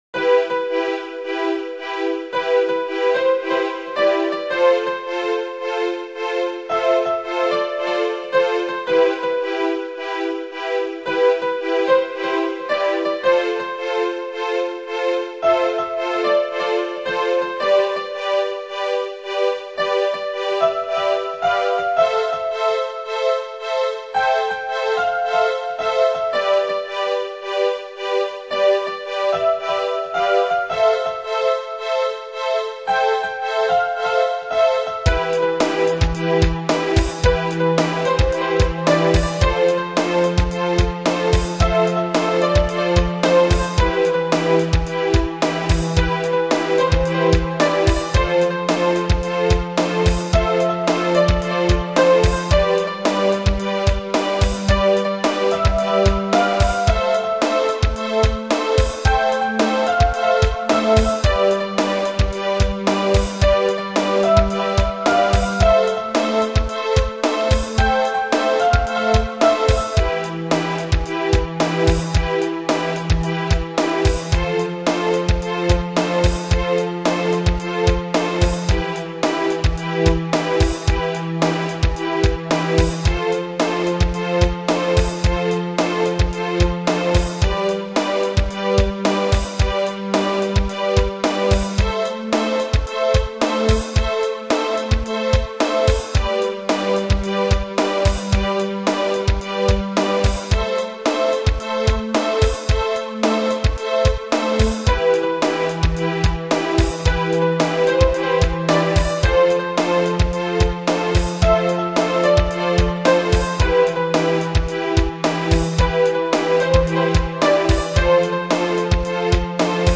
ヒップホップ（？）
季節的に秋のメランコリー（？）な気分も反映しています。